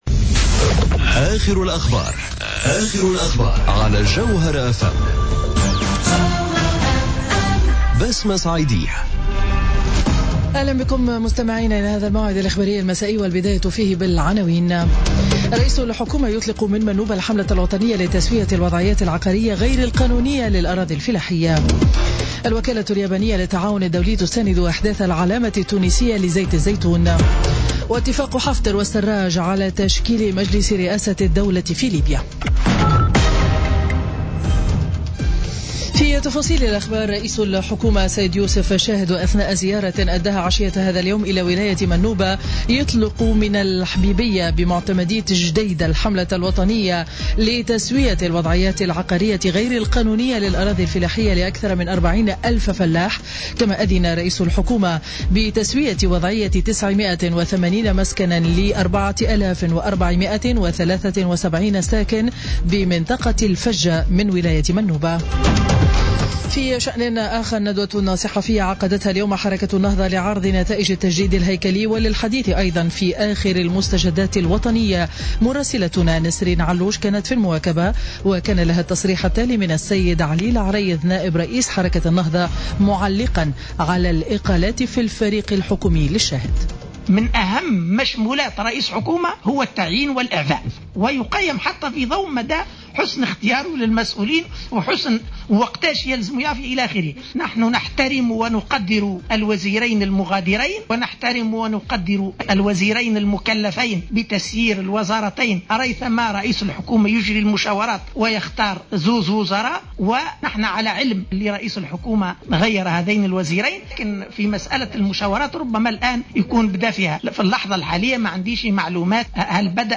نشرة أخبار السابعة مساء ليوم الثلاثاء 2 ماي 2017